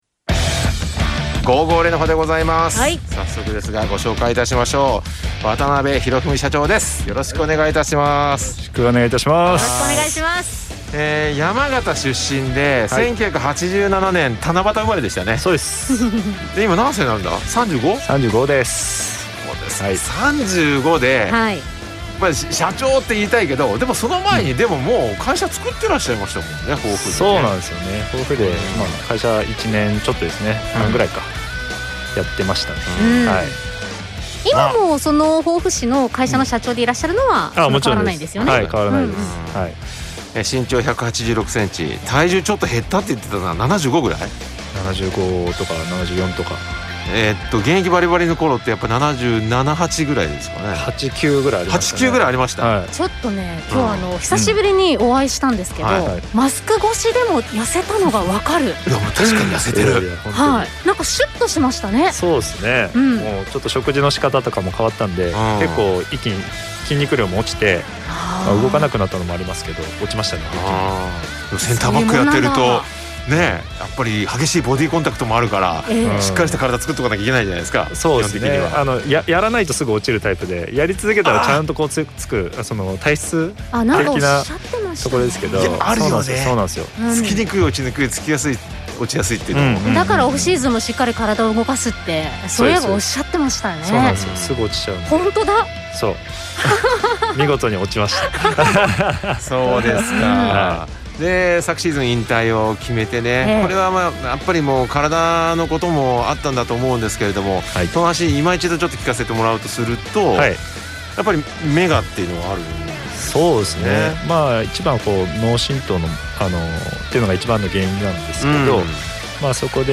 生出演です。